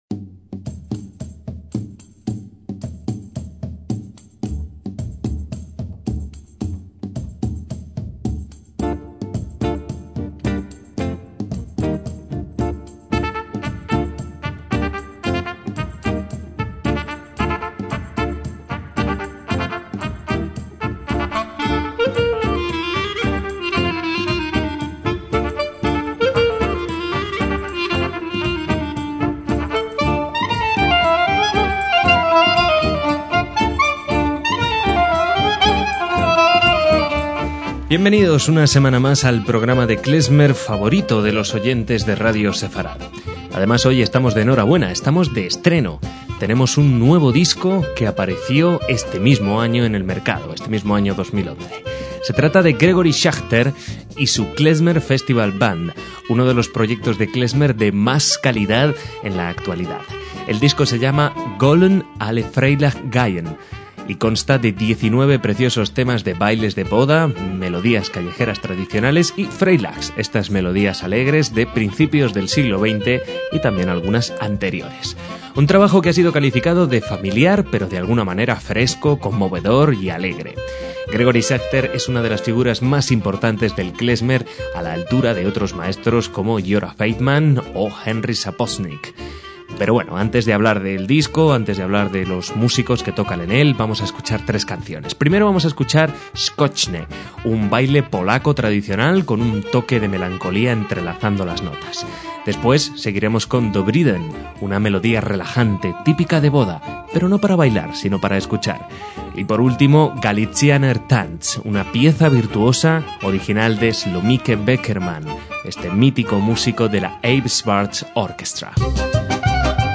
MÚSICA KLEZMER
clarinete